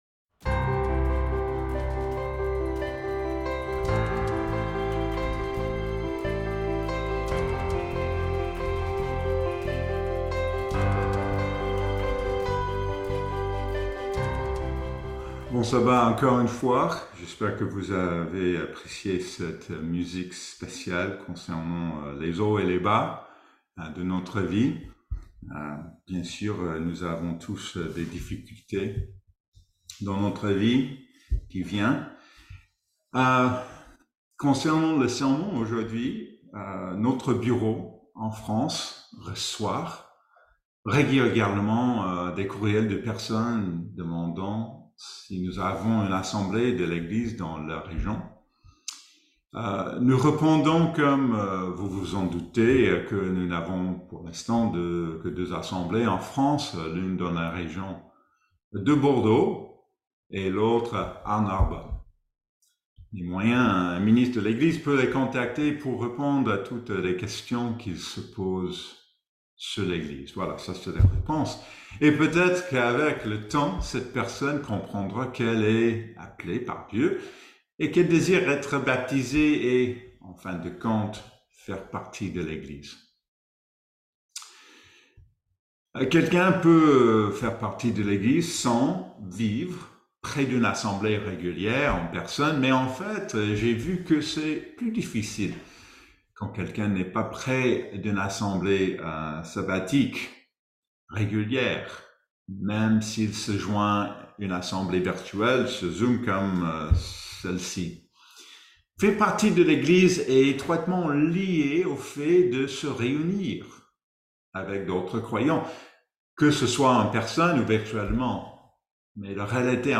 Il explore comment nous pouvons contribuer activement à la mission de l'Église, même en étant éloignés physiquement les uns des autres. Ce sermon nous encourage à réfléchir sur notre engagement personnel envers l'Église et à découvrir comment chacun de nous peut servir et renforcer la communauté chrétienne.